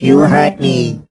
rick_hurt_01.ogg